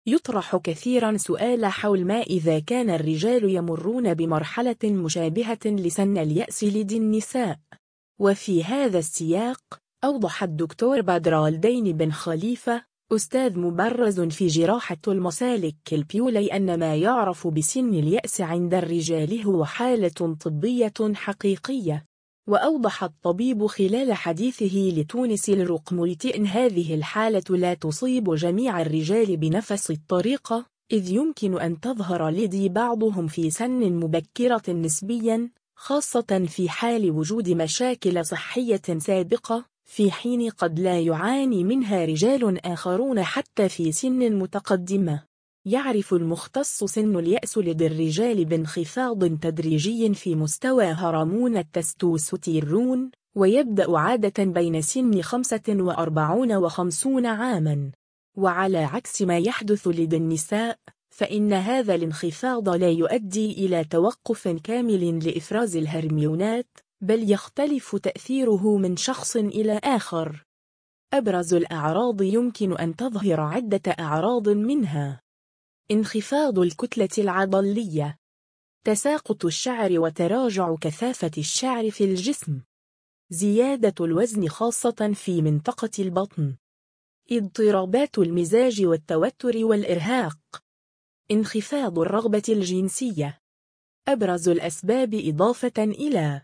وأوضح الطبيب خلال حديثه لـتونس الرقميةأن هذه الحالة لا تصيب جميع الرجال بنفس الطريقة، إذ يمكن أن تظهر لدى بعضهم في سن مبكرة نسبياً، خاصة في حال وجود مشاكل صحية سابقة، في حين قد لا يعاني منها رجال آخرون حتى في سن متقدمة.